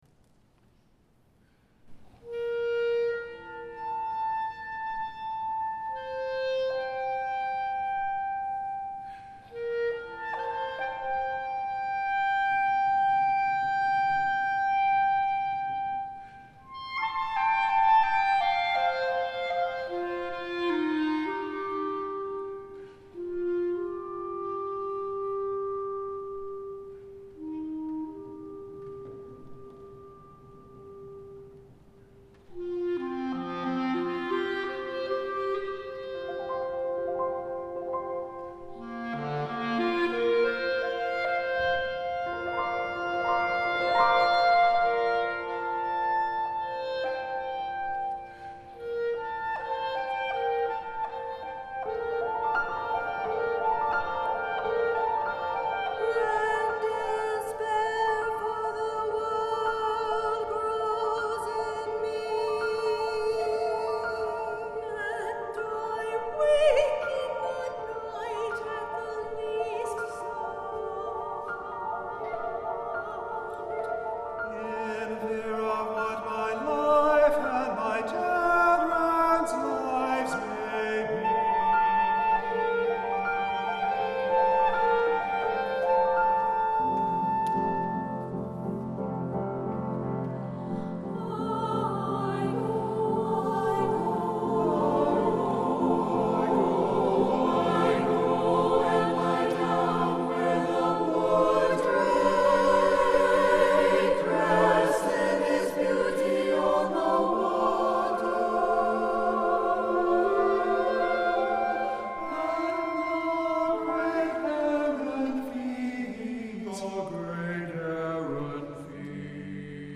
for SATB Chorus, Clarinet, and Piano (2009)
These songs are scored for SATB chorus.